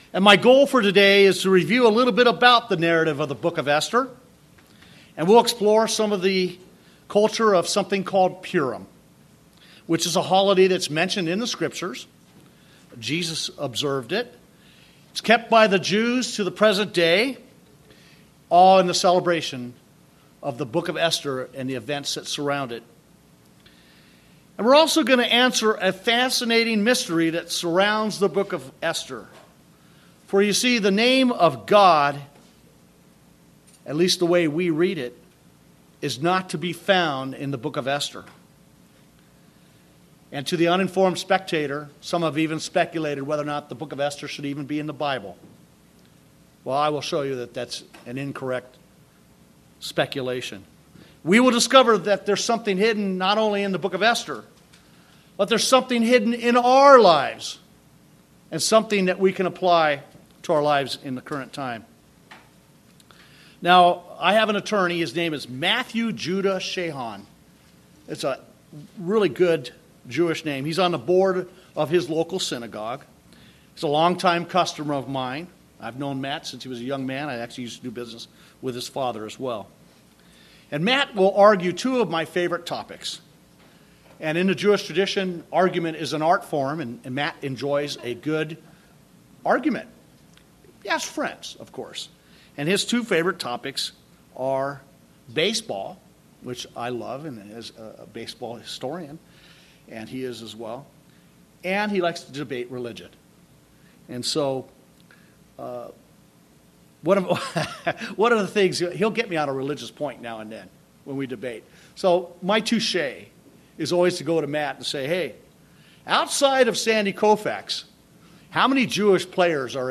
Sermons
Given in Redlands, CA